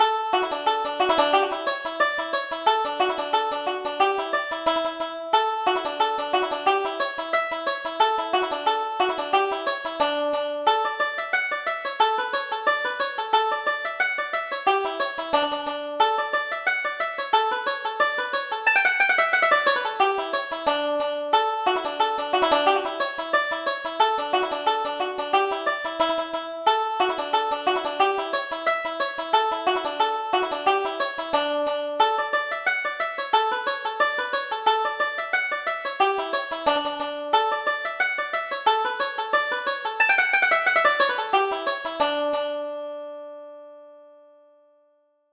Reel: Repeal of the Union